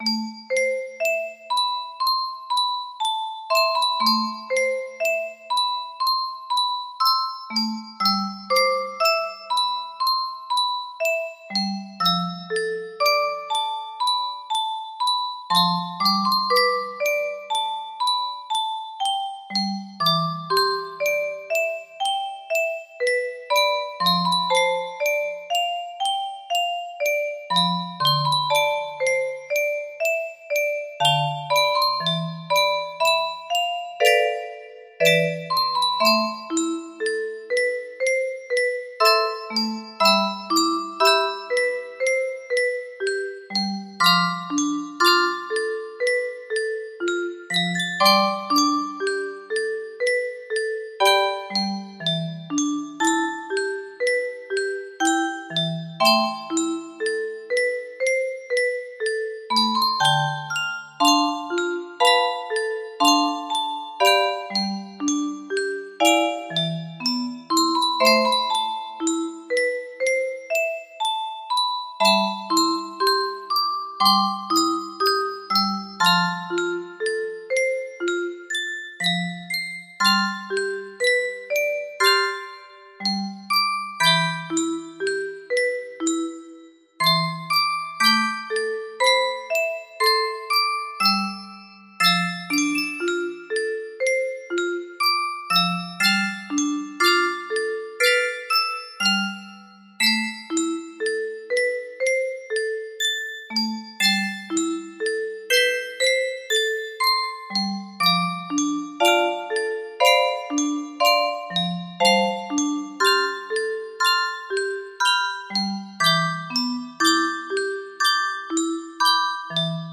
Full range 60
Either way i thought it would make a nice music box.